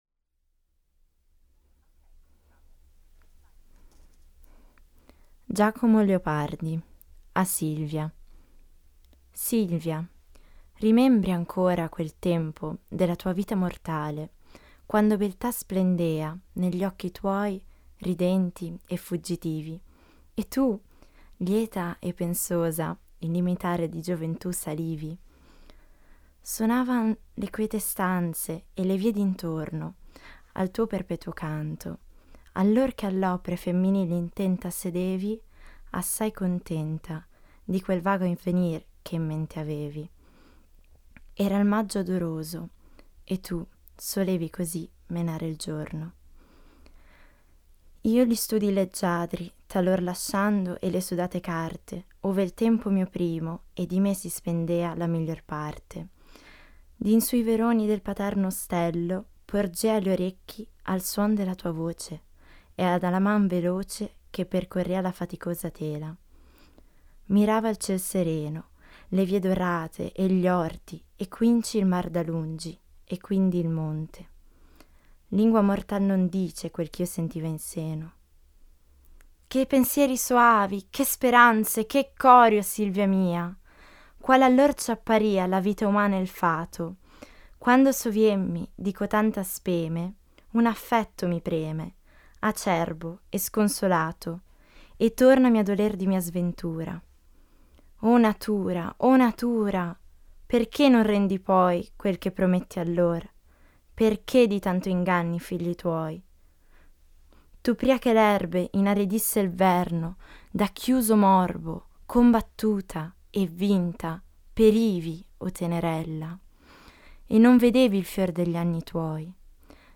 Poesie
Madrelingua italiani interpretano celebri poesie.